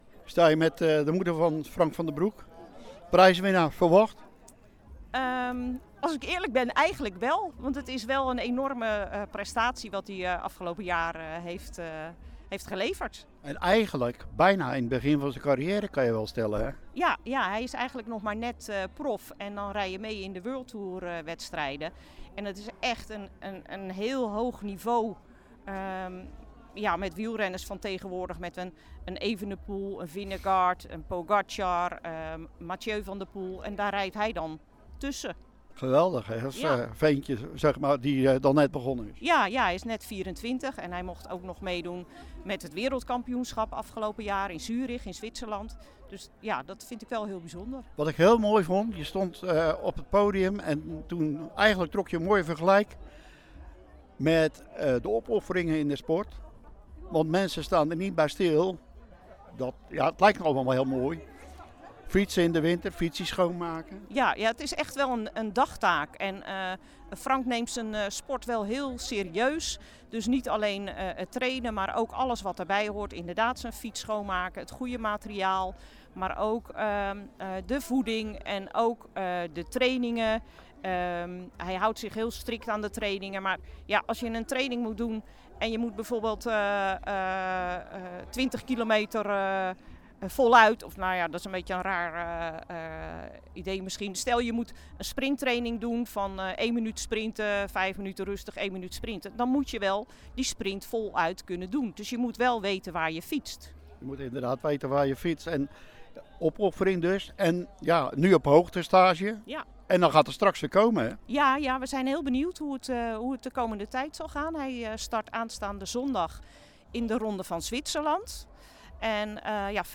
Teylingen – Tijdens het Sportgala Teylingen zijn donderdagavond de jaarlijkse sportprijzen uitgereikt.